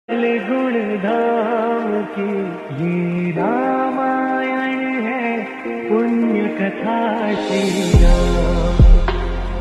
devotional hindu ram ringtone